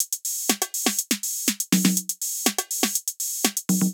AIR Beat - Perc Mix 1.wav